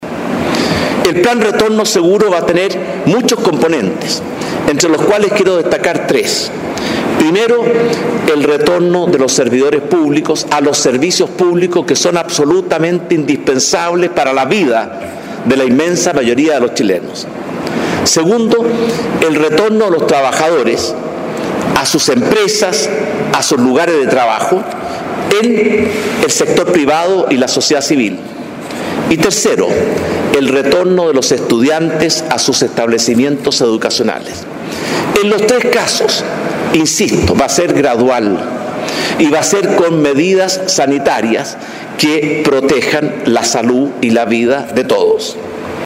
En un punto de prensa en La Moneda, el mandatario indicó que existirán tres fases, donde los empleados públicos serán los primeros en volver, para luego los privados.